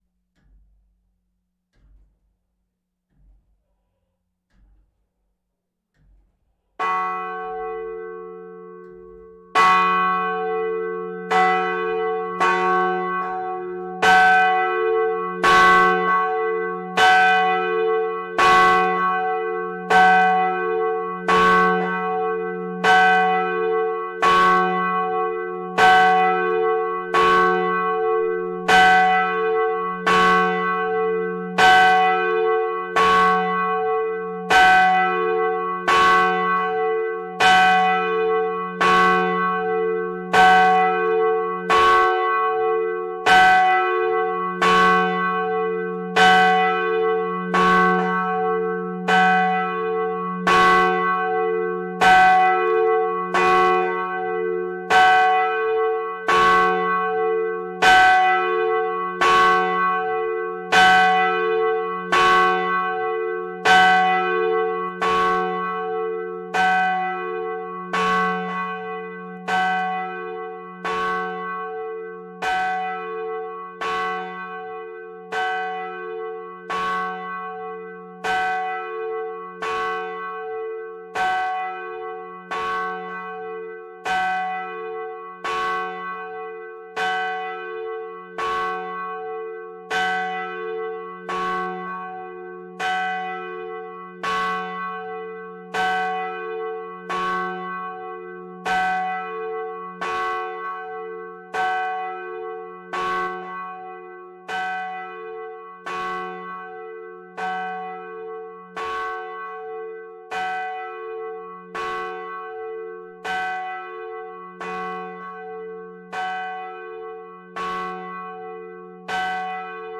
4. Kerkklok (bel)
Het klokgeluid